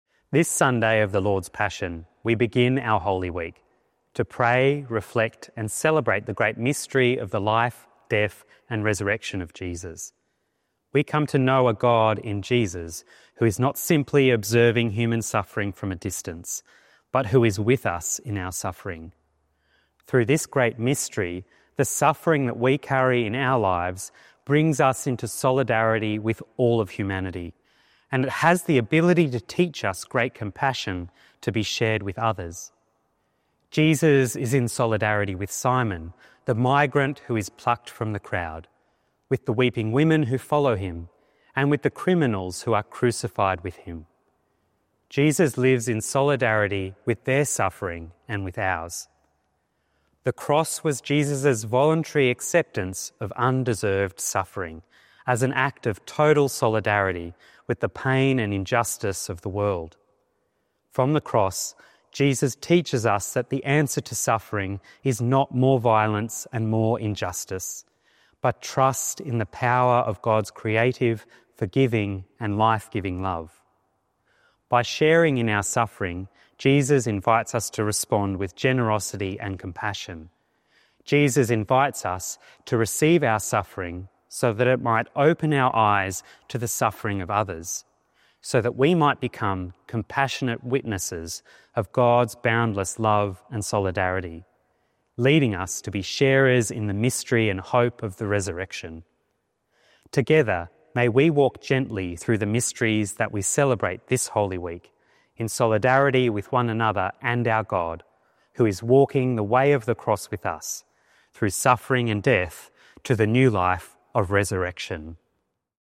Archdiocese of Brisbane Palm Sunday - Two-Minute Homily